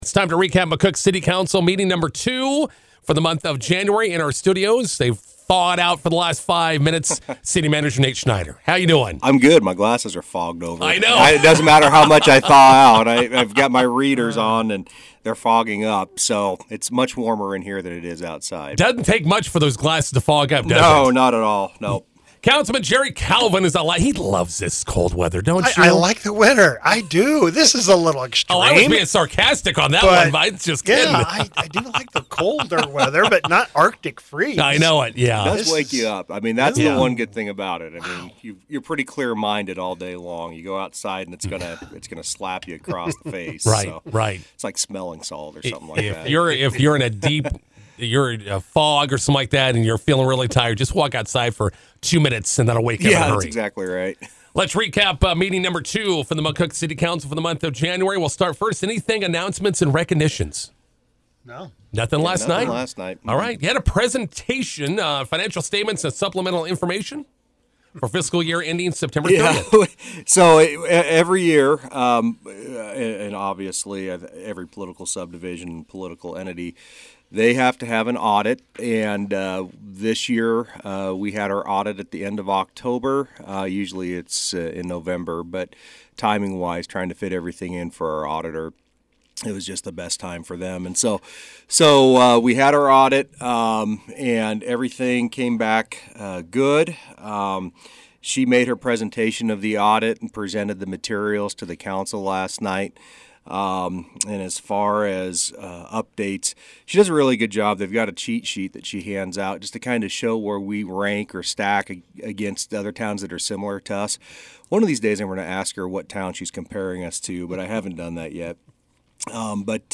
INTERVIEW: McCook City Council meeting recap with City Manager Nate Schneider and Councilman Jerry Calvin. | High Plains Radio